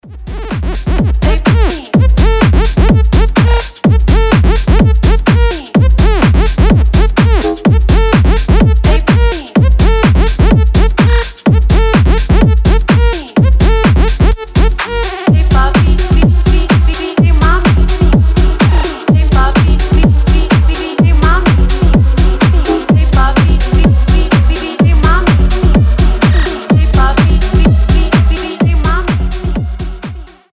electro house , edm , tech house